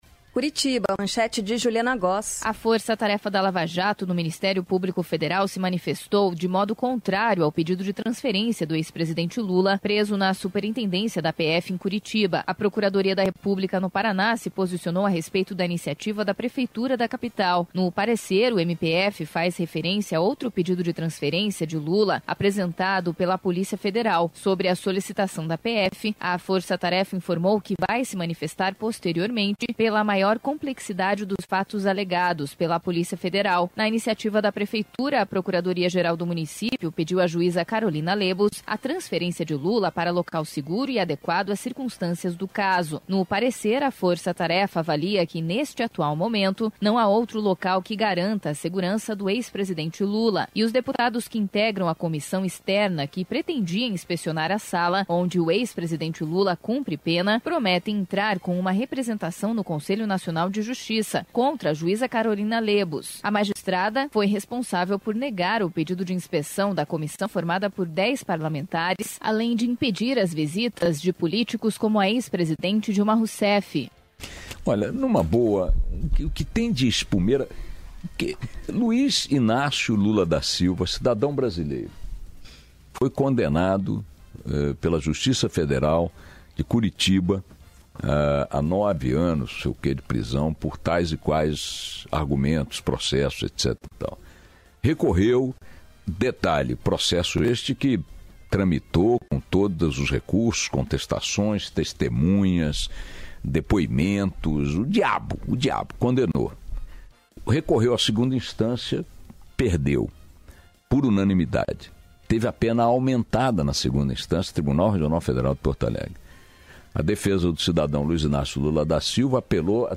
Boechat, Indignado, Fala Sobre a Transferência de Lula
Ricardo Boechat, em seu programa no dia 26 na BandNews, comentou sobre o pedido de transferência de Lula e falou sobre as condições da prisão.